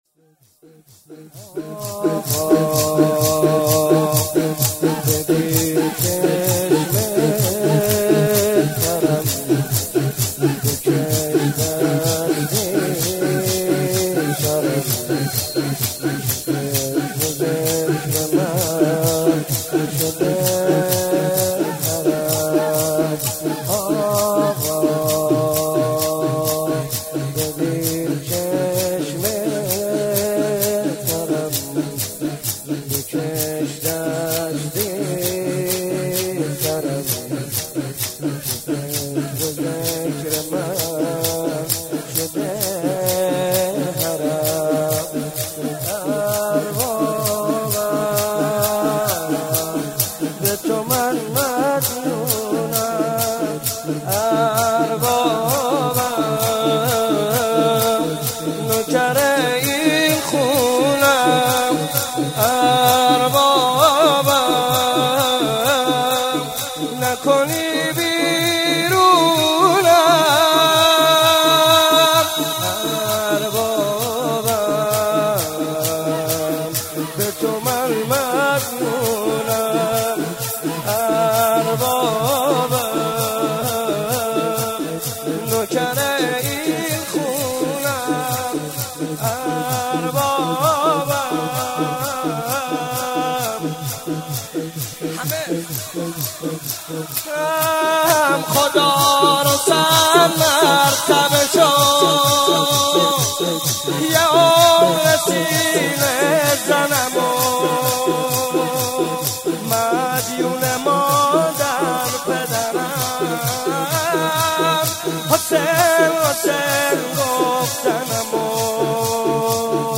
واحد، زمینه